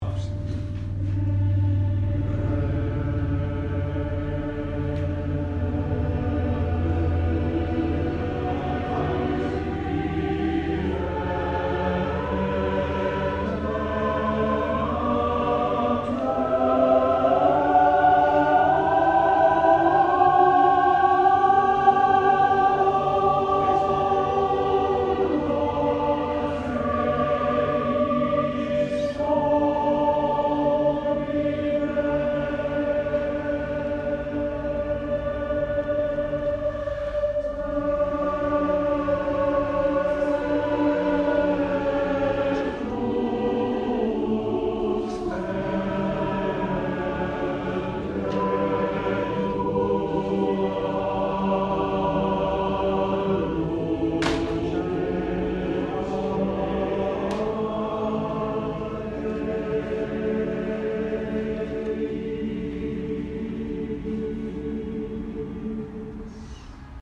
If you put all those works together, you have about an hour of a cappella music by Italian composers in Latin and Italian.
and performed without a piano
Here’s a taste of the Pizzetti, from our Thursday rehearsals, that gives you a hint of the remarkable G Major beauty that raises its head above the clouds.
Pizzetti-Requiem-rehearsal.mp3